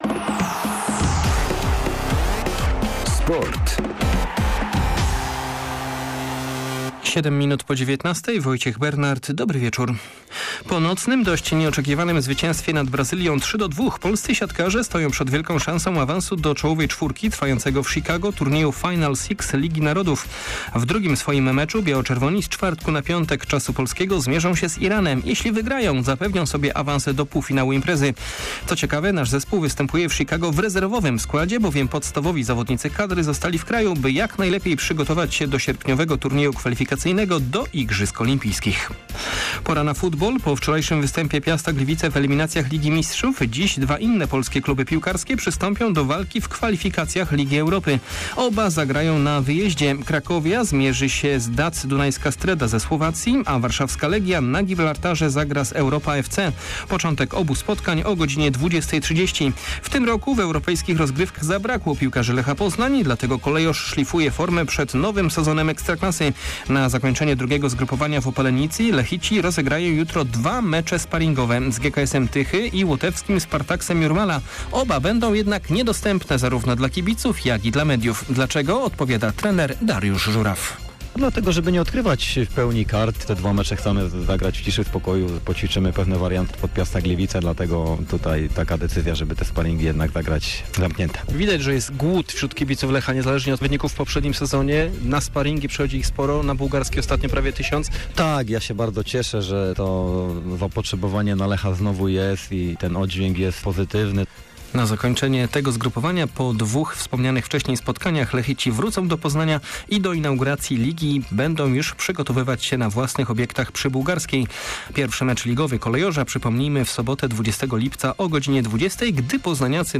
11.07. SERWIS SPORTOWY GODZ. 19:05